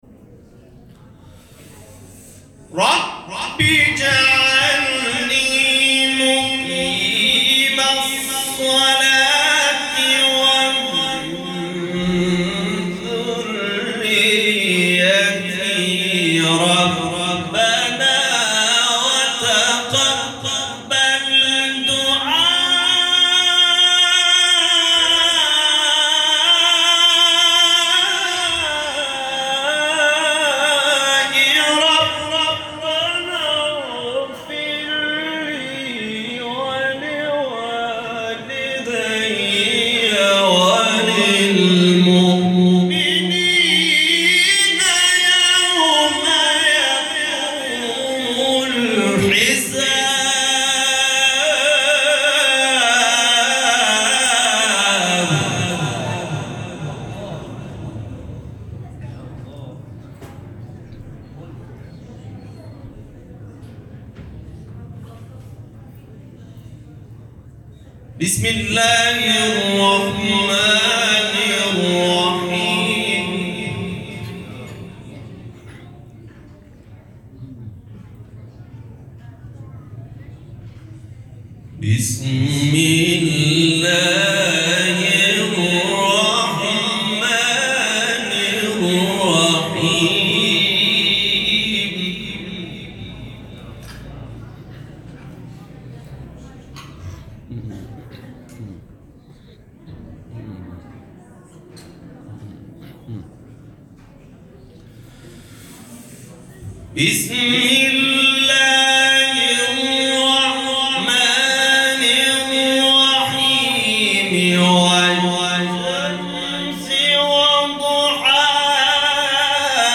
به گزارش خبرگزاری بین‌المللی قرآن(ایکنا) مقاطع صوتی از تلاوت قاریان بین‌المللی و ممتاز کشور که به تازگی در شبکه‌های اجتماعی منتشر شده است، ارائه می‌شود.
فرازی از تلاوت